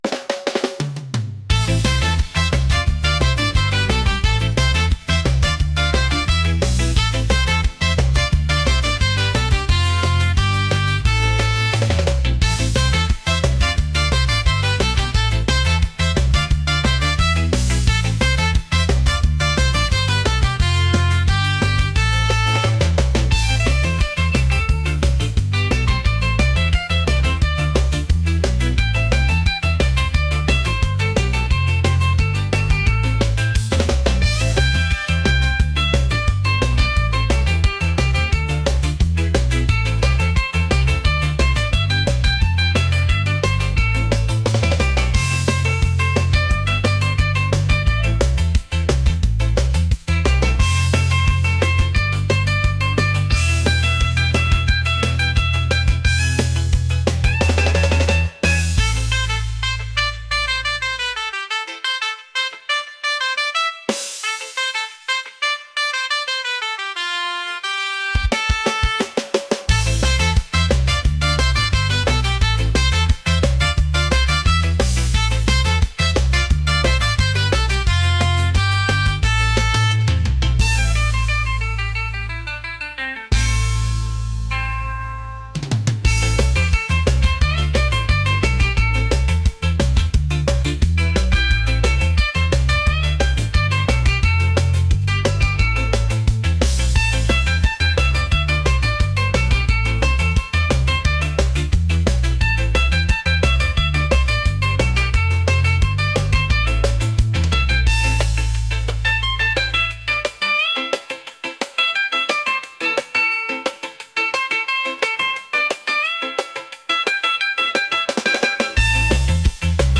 reggae | energetic